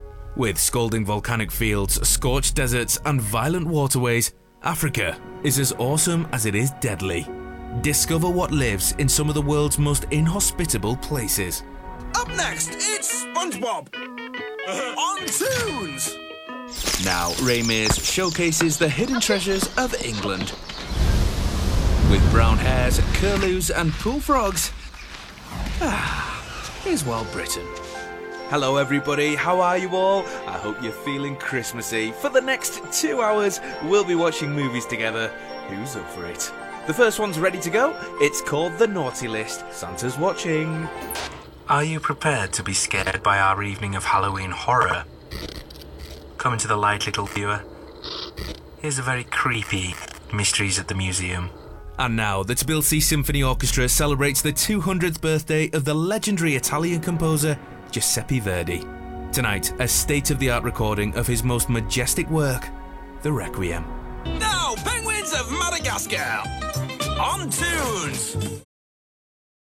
Continuity Showreel
Male
Cumbrian
Manchester
Northern
Friendly
Voice Next Door
Confident